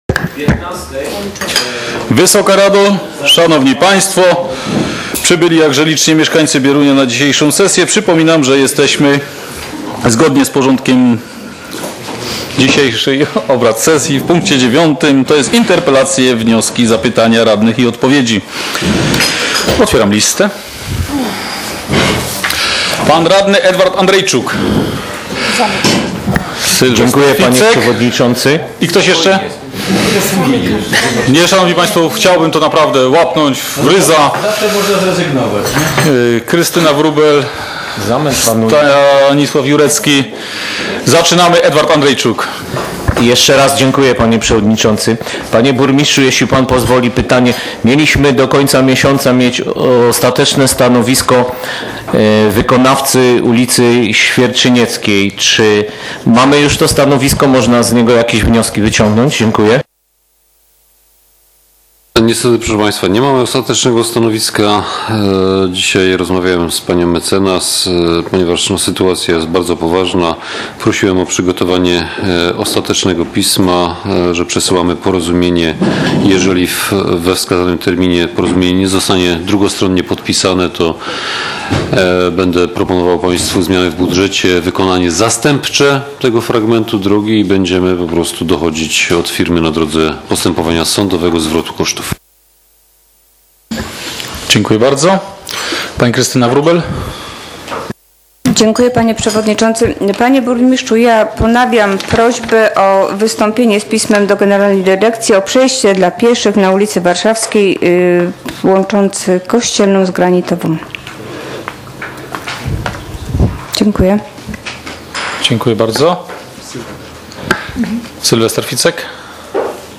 z obrad VII sesji Rady Miejskiej w Bieruniu, która odbyła się w dniu 30.06.2016 r.